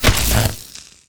flesh2.wav